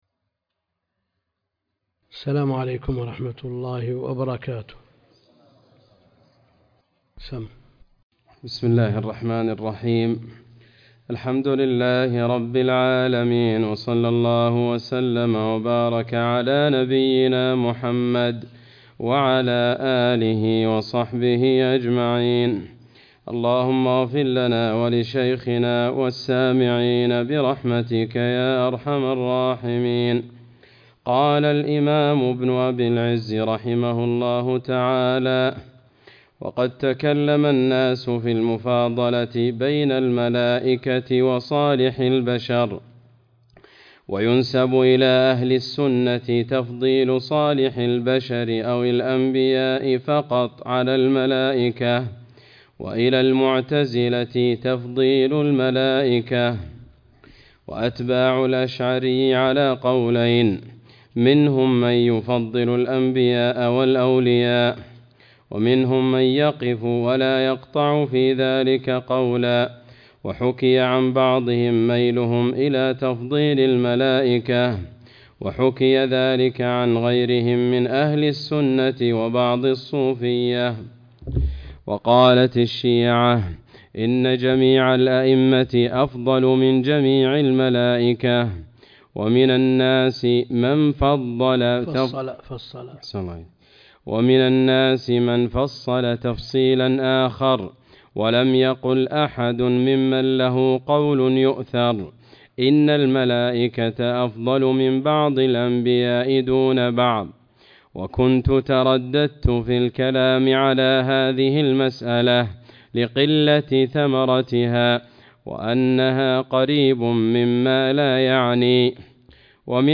عنوان المادة الدرس (44) شرح العقيدة الطحاوية تاريخ التحميل السبت 21 يناير 2023 مـ حجم المادة 23.88 ميجا بايت عدد الزيارات 205 زيارة عدد مرات الحفظ 109 مرة إستماع المادة حفظ المادة اضف تعليقك أرسل لصديق